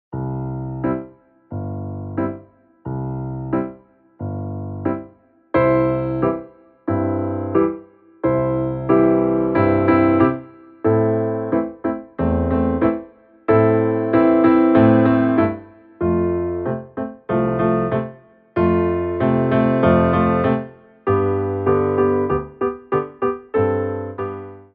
CHANGE OF TEMPO